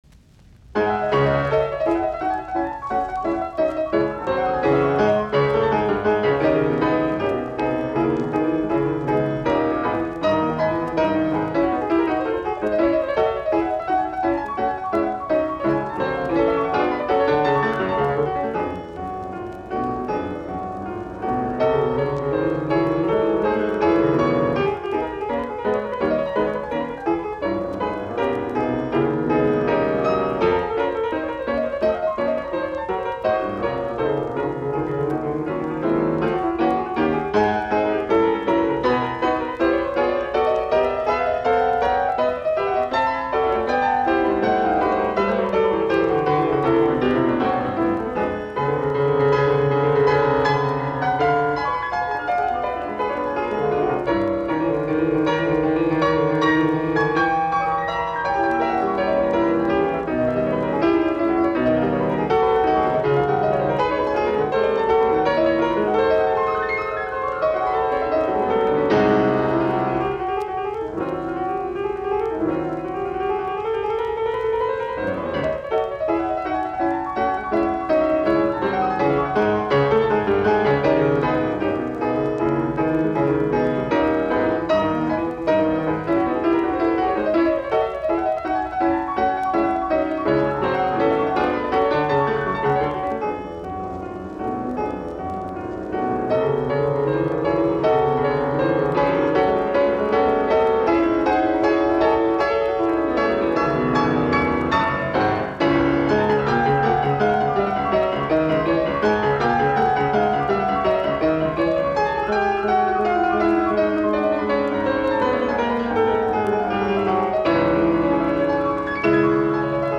Etydit, piano, op10
Soitinnus: Piano.